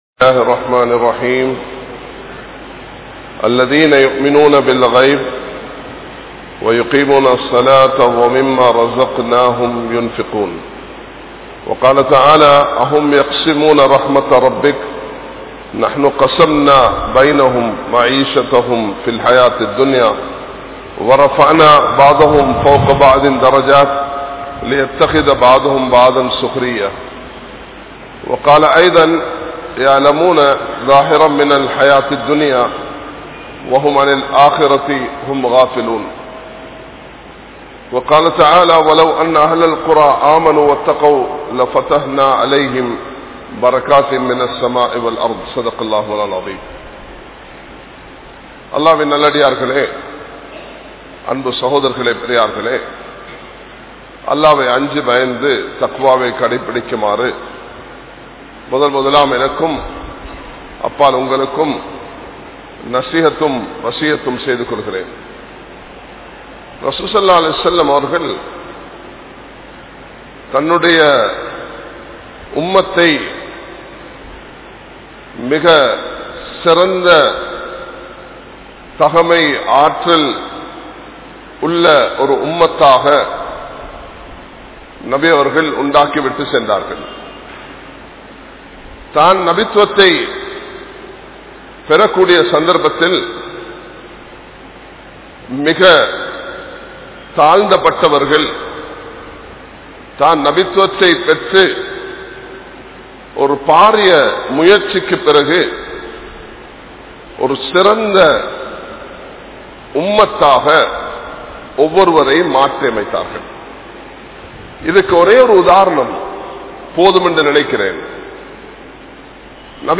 Alinthu Vidum Ulahamum Niranthara Marumaium (அழிந்து விடும் உலகமும் நிரந்தர மறுமையும்) | Audio Bayans | All Ceylon Muslim Youth Community | Addalaichenai
Kandy, Line Jumua Masjith